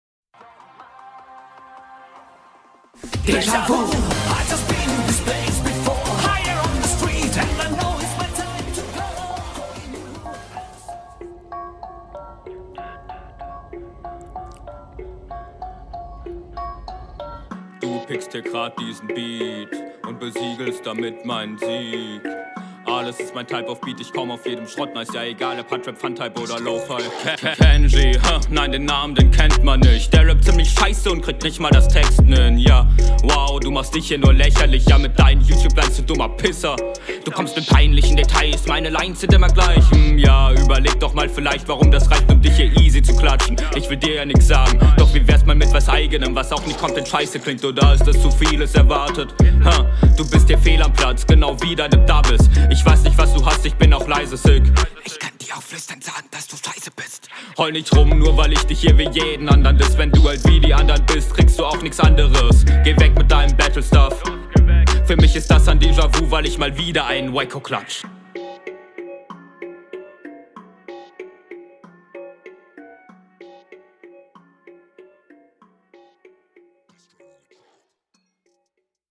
Flow: Deutlich unterlegen.